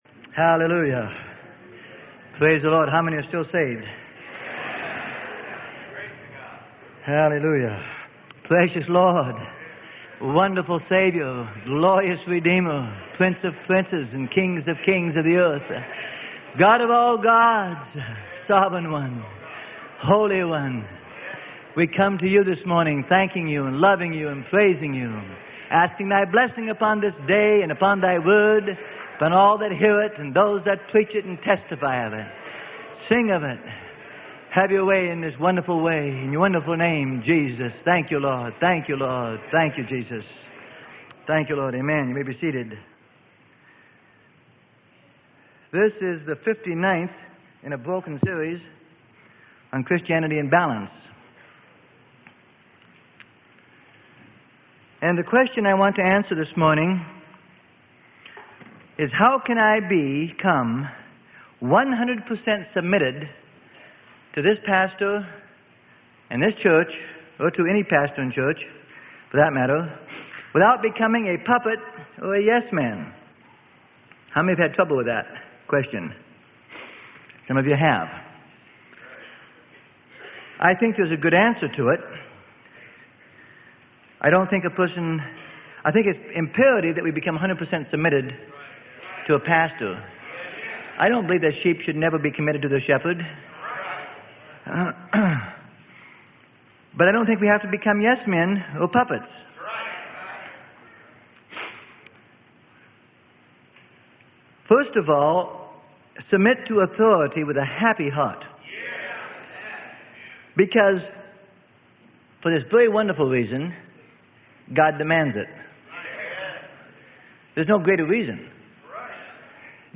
Sermon: CHRISTIANITY IN BALANCE.